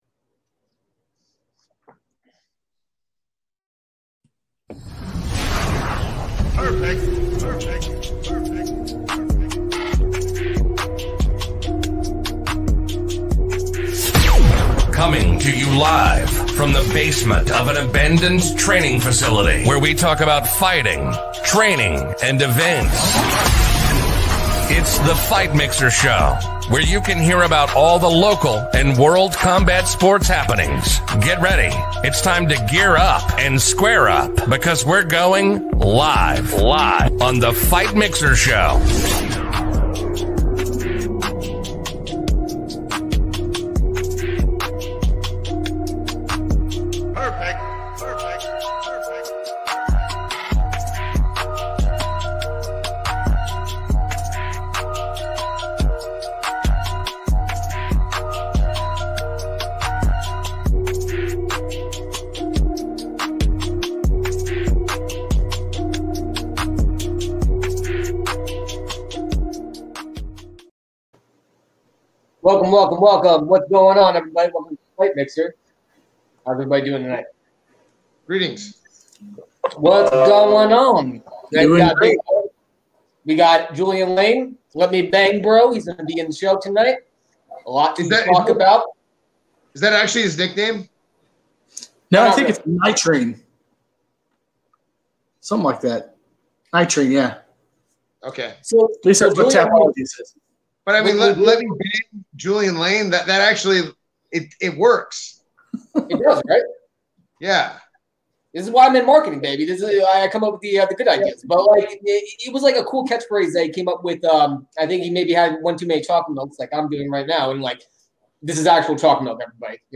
Interview - Fight Mixer